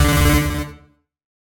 android_frameworks_base / data / sounds / notifications / alert13.ogg
alert13.ogg